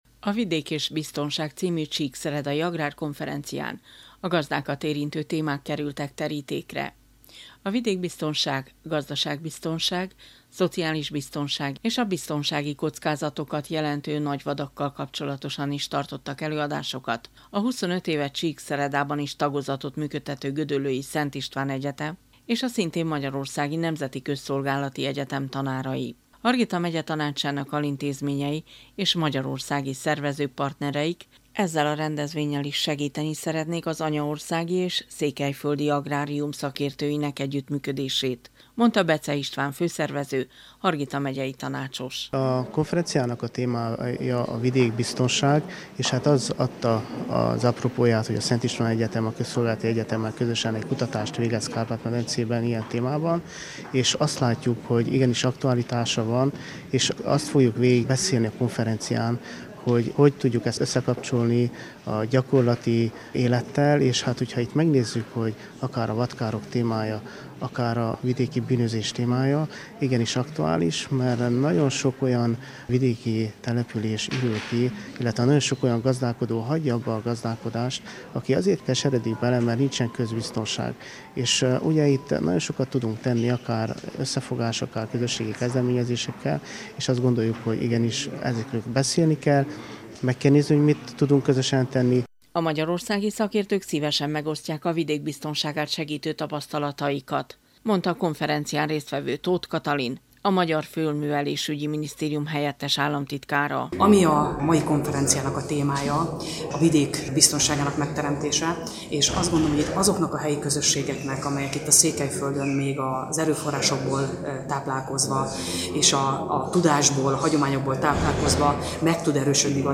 beszámolója.